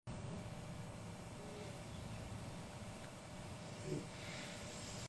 Satisfying Pimple Popper!